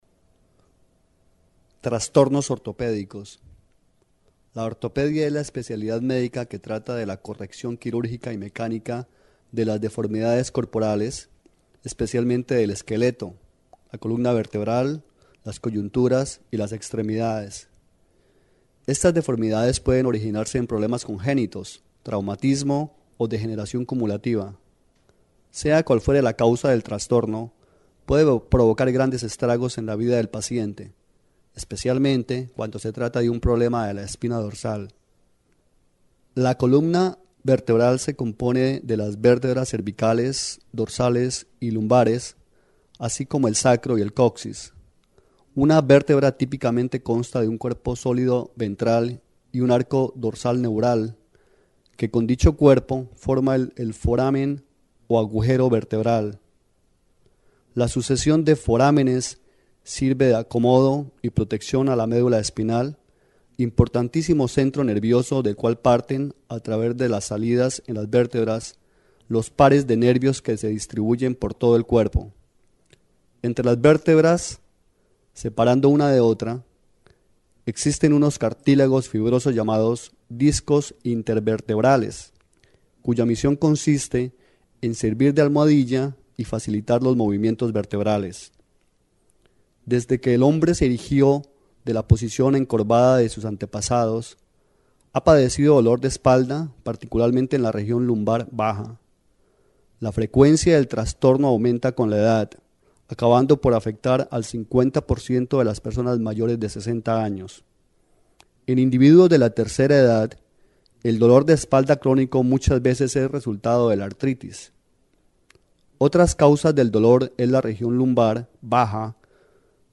Monologue 2 Audio only -
Track 09 Monologue02.mp3